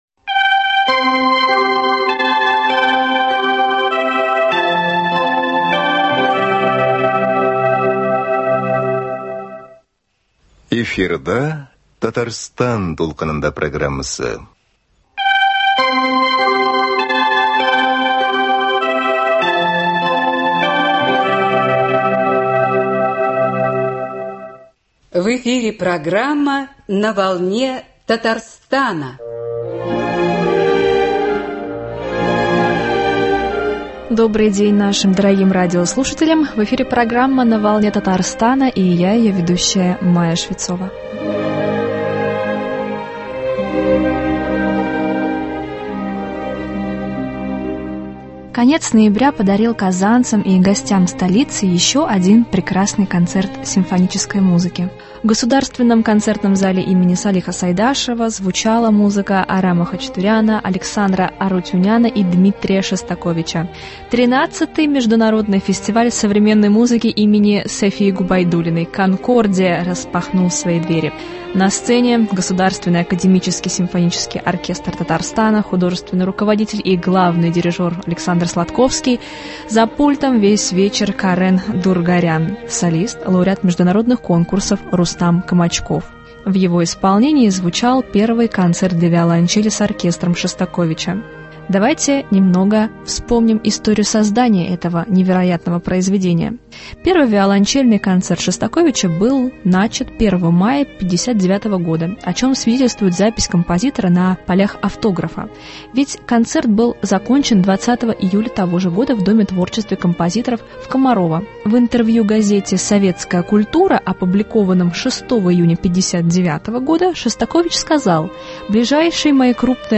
В передаче «На волне Татарстана» музыкант поделился планами о будущем сезоне, сотрудничестве с ГАСО РТ, молодых исполнителях и современной композиторской школе.